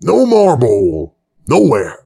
brickmove05.ogg